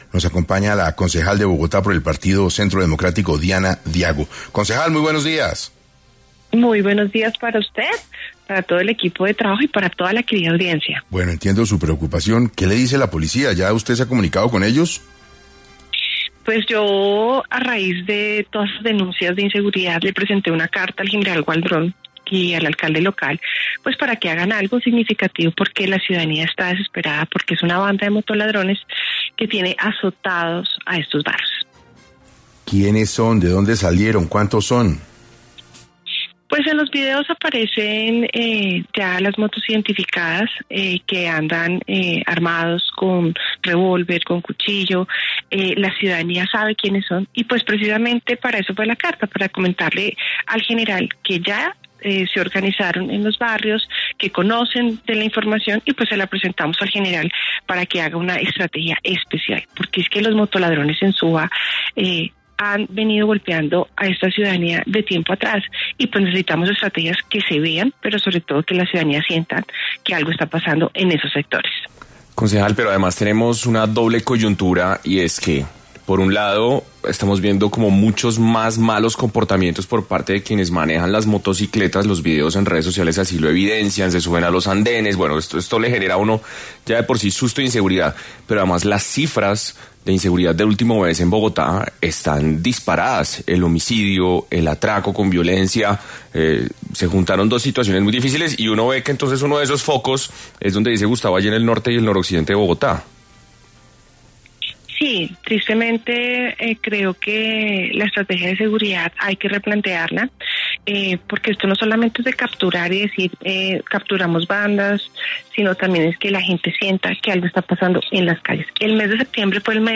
En 6AM de Caracol Radio estuvo la concejal de Bogotá por el Centro Democrático, Diana Diago, para hablar sobre las zonas más afectadas por los “motoladrones” en Bogotá, especialmente en Suba y cuál ha sido la respuesta de las autoridades.